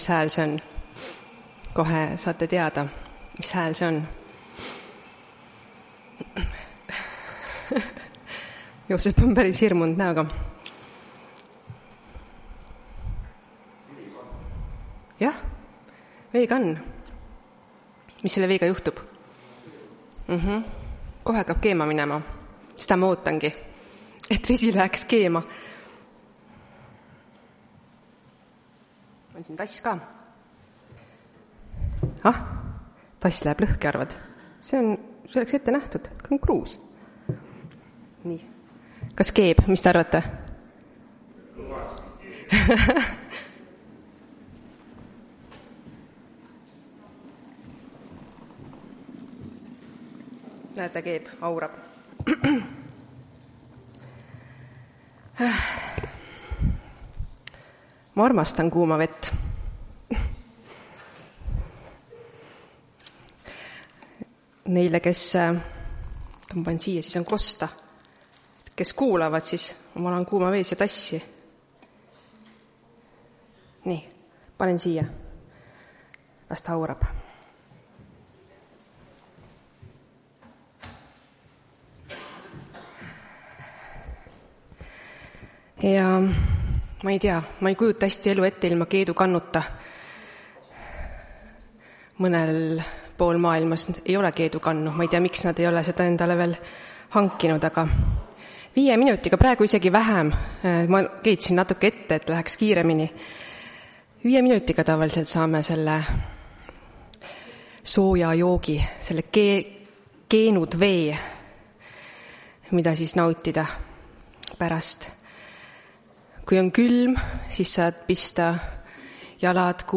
Tartu adventkoguduse 20.09.2025 teenistuse jutluse helisalvestis.
Jutlused